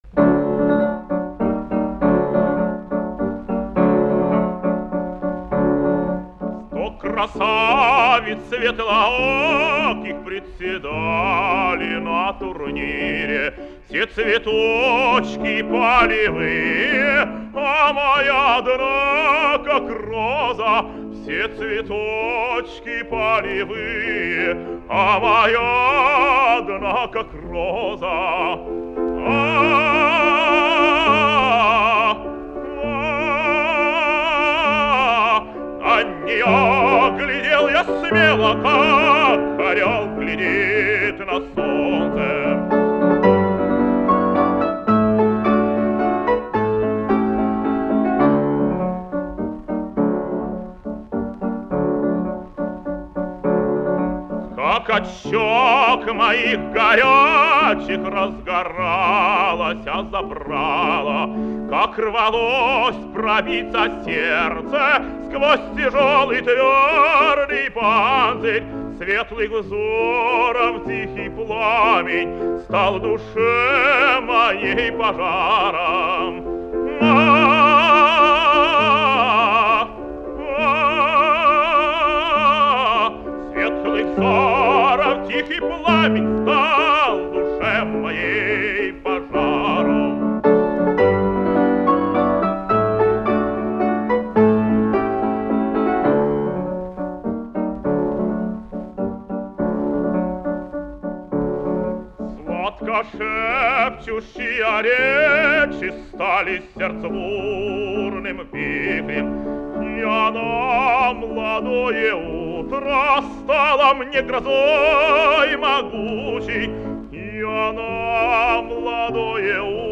Певцы